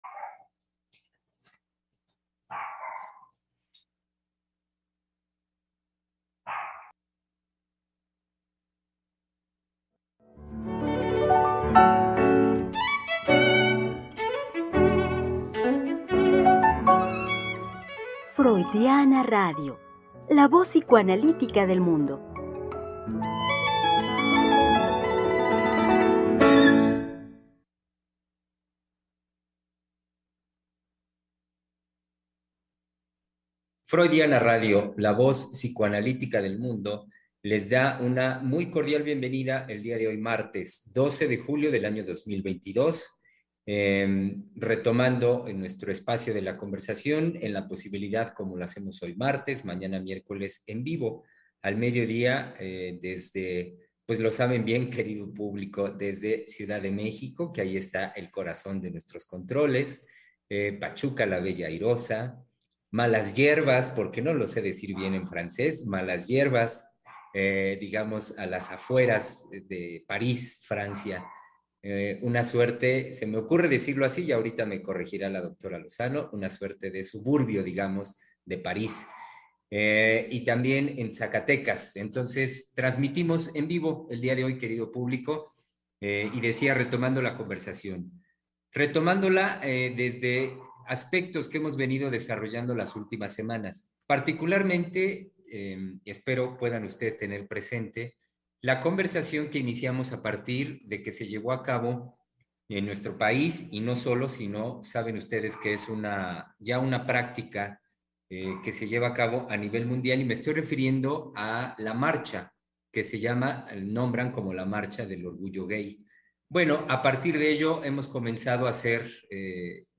Programa transmitido el 13 de julio del 2022.